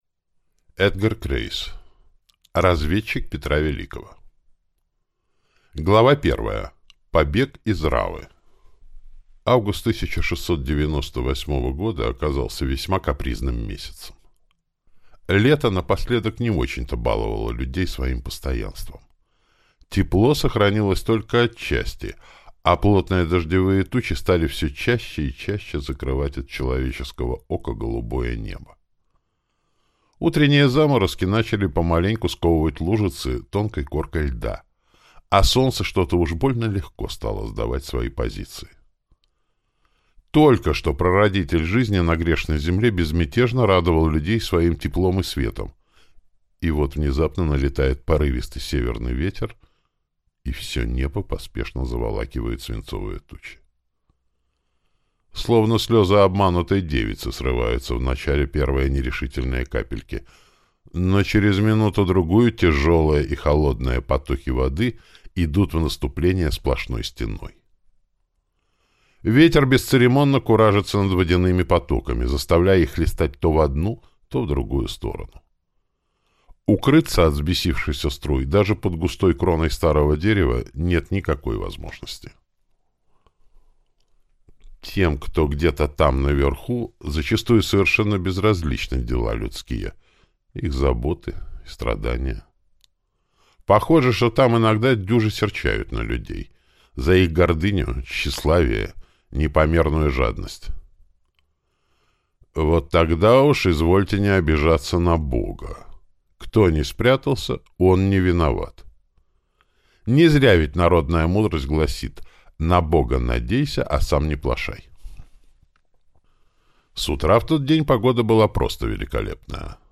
Аудиокнига Разведчик Петра Великого | Библиотека аудиокниг